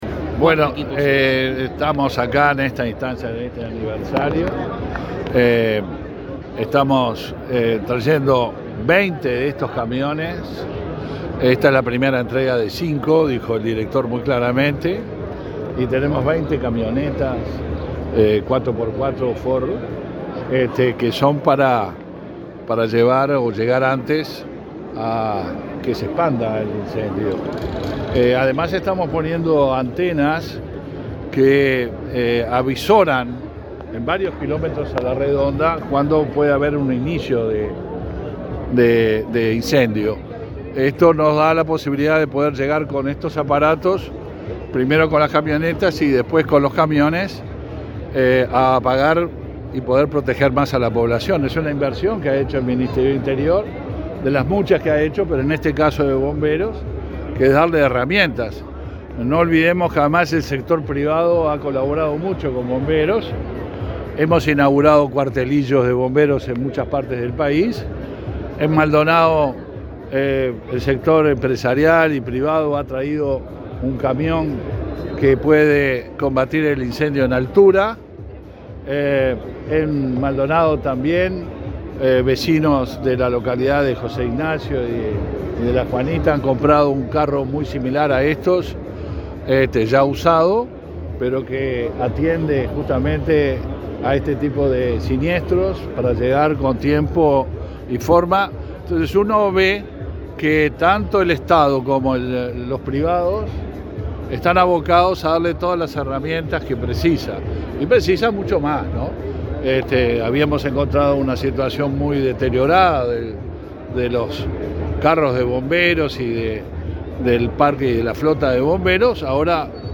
Declaraciones del ministro del Interior, Luis Alberto Heber
El ministro del Interior, Luis Alberto Heber, dialogó con la prensa, luego de participar de la celebración del 136.° aniversario del Servicio de